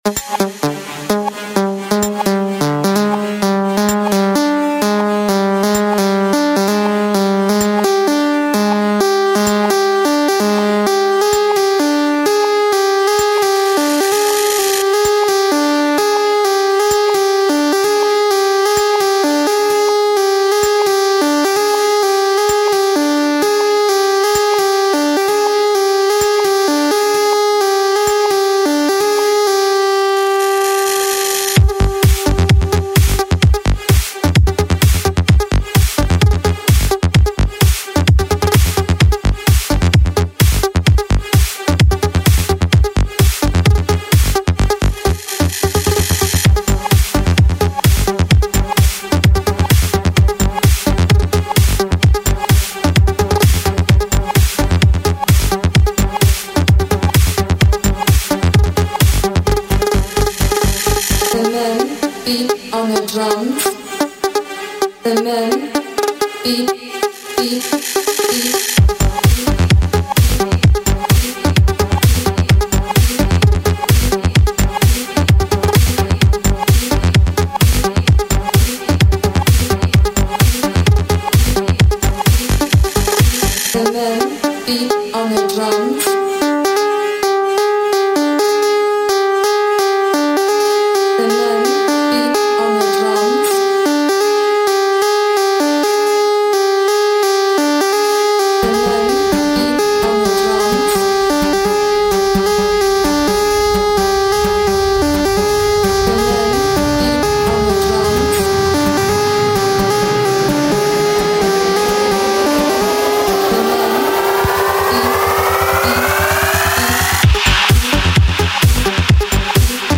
Жанр: House - Electro